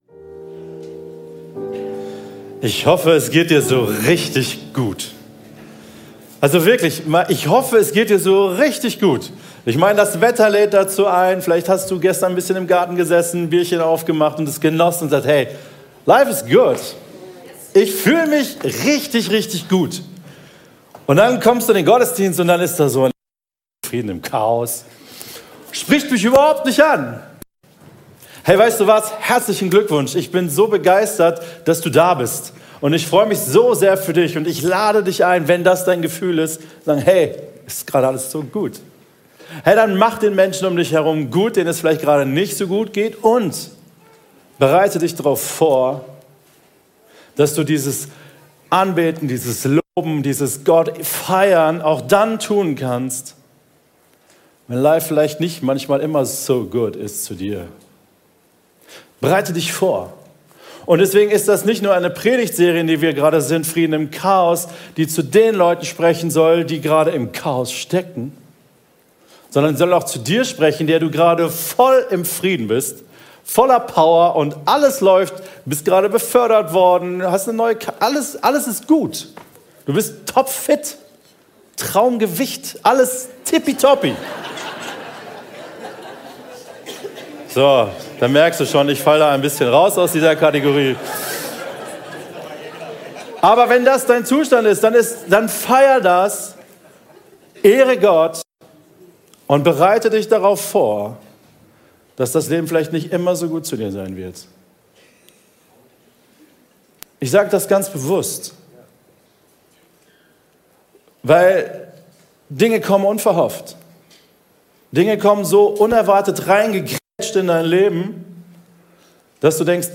Predigten der Treffpunkt Leben Gemeinde, Erkrath